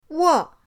wo4.mp3